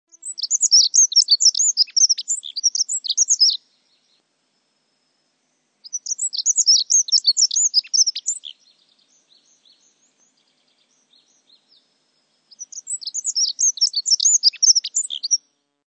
Pokrzywnica - Prunella modularis